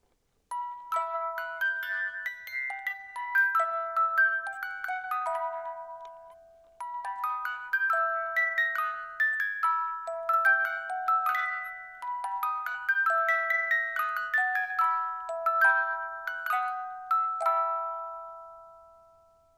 die Spieluhr besitzt ein Qualitätsspielwerk mit 18 Zungen
So ist Bambus teilweise härter und haltbarer als Holz und besitzt dazu hervorragende Klangeigenschaften. Der Ton dieser Spieluhren ist klar, warm und obertonreich und wird durch Kurbeln eines Qualitätsspielwerks erzeugt.
• the music box is a mechanical musical instrument and expressly not a toy